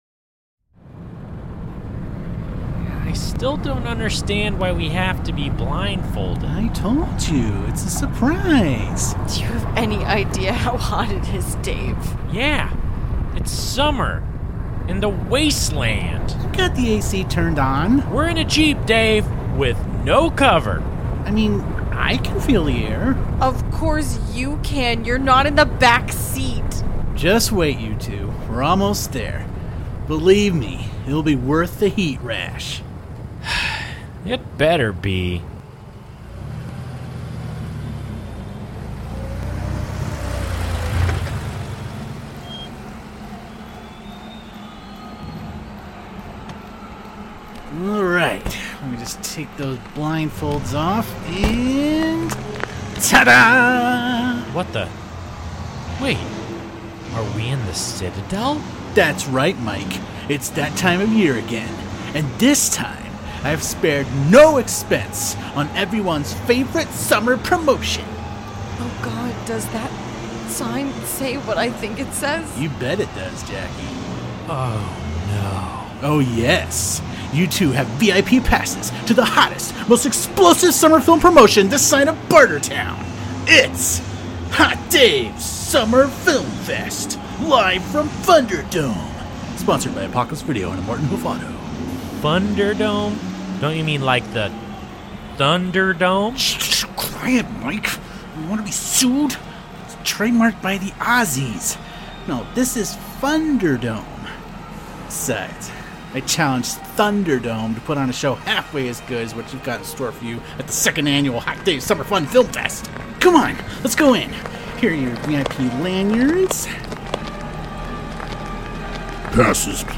Live from Funderdome .